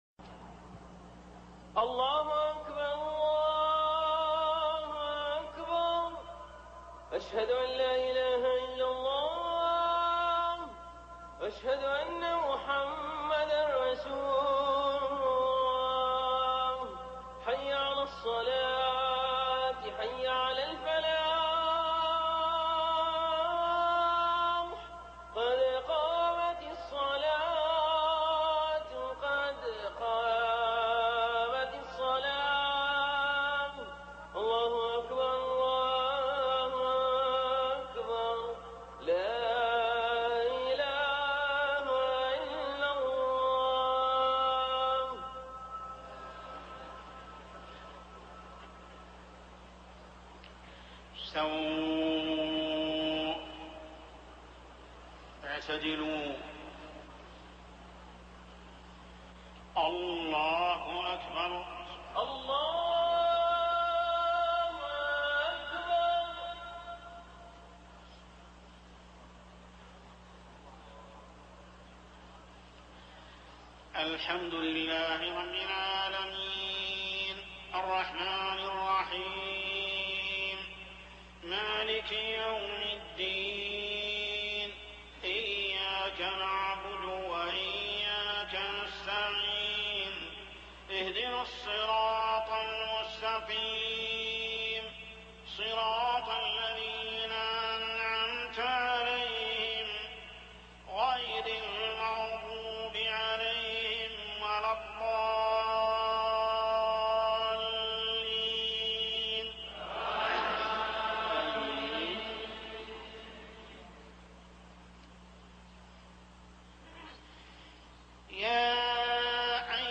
صلاة العشاء رمضان عام 1419هـ من سورة الأحزاب 41-48 > 1419 🕋 > الفروض - تلاوات الحرمين